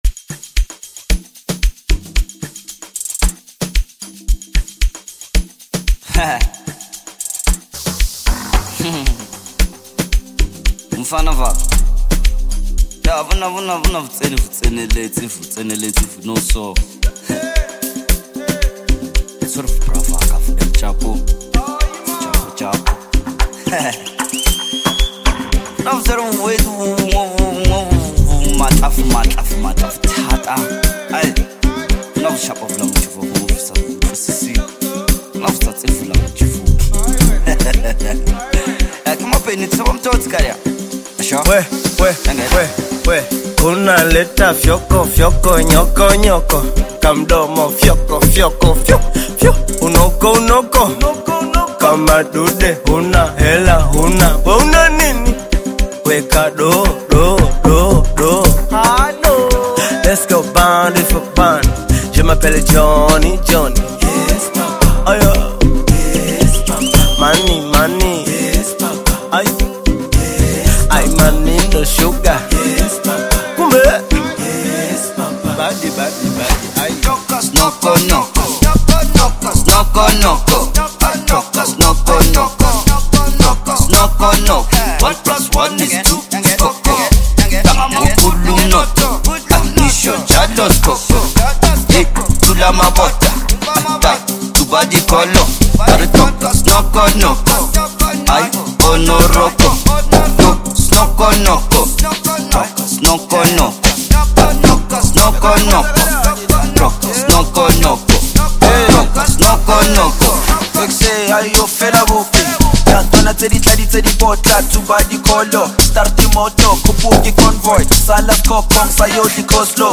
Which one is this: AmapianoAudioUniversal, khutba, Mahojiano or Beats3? AmapianoAudioUniversal